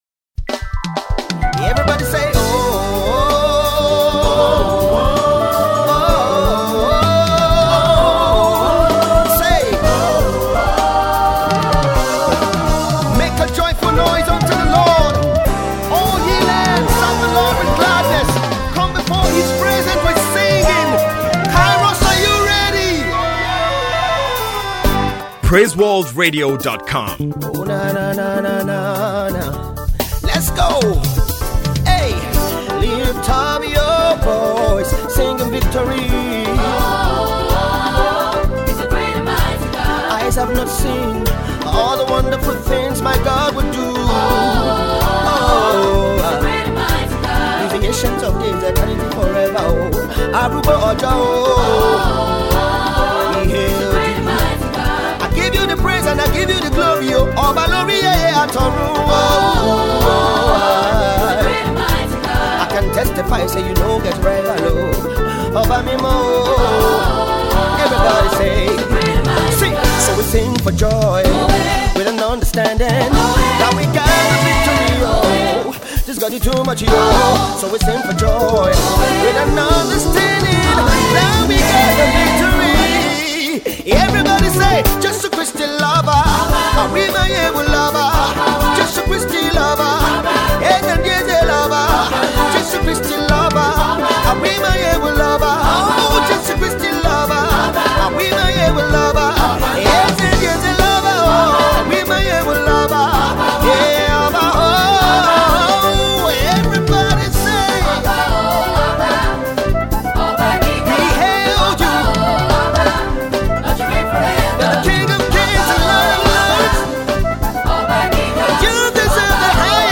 a praise jam